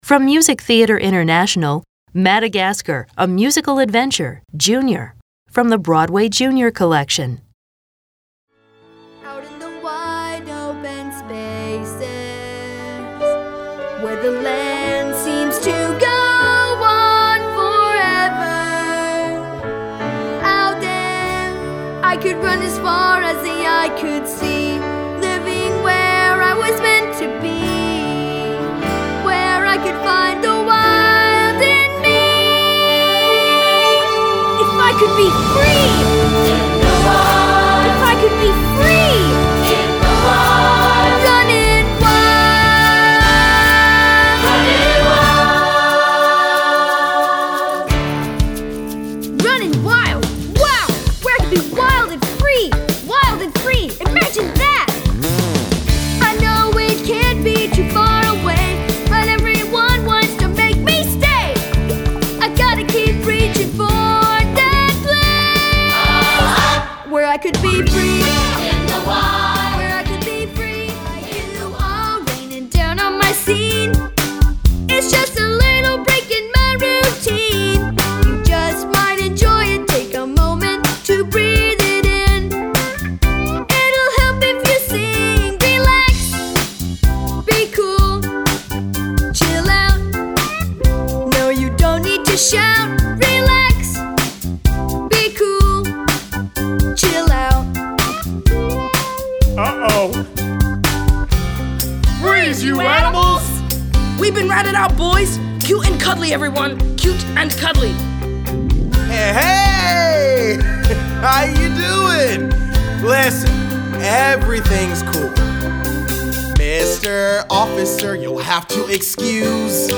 General Music Showkits Broadway Junior